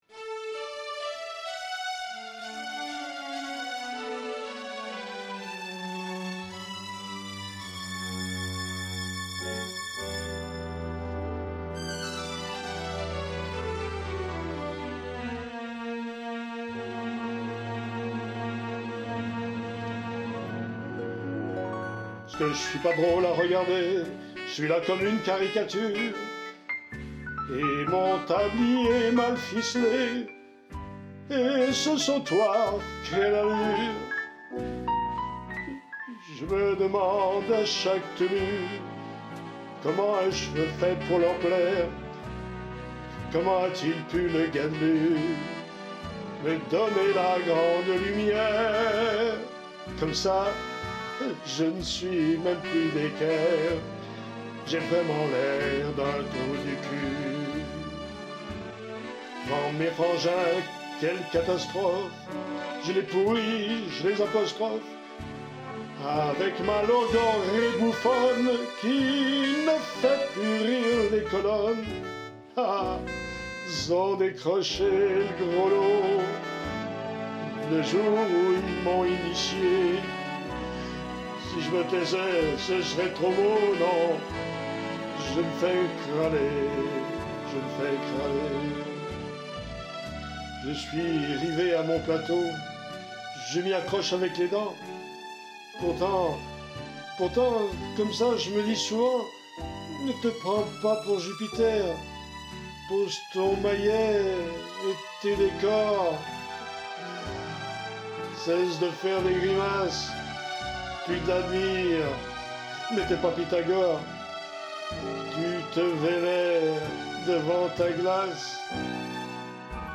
Sketch burlesque, loufoque